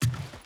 Wood Jump.wav